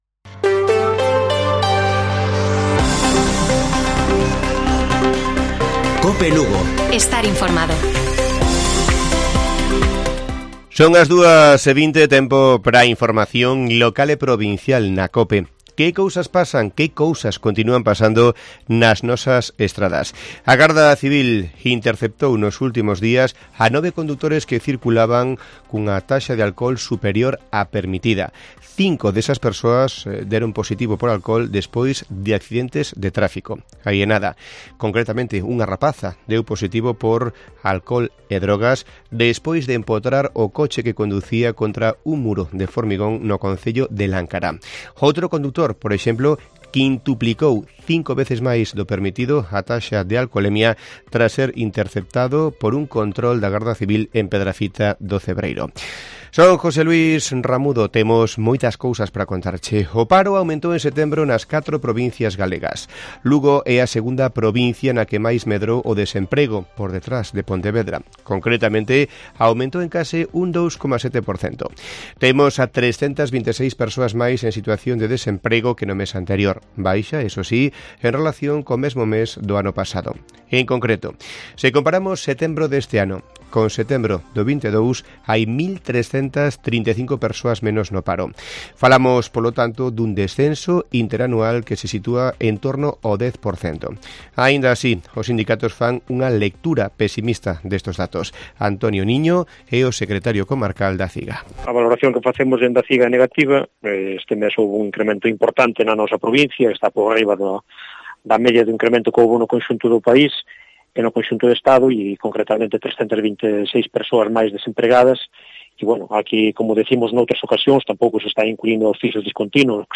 Informativo Mediodía de Cope Lugo. 3 DE OCTUBRE. 14:20 horas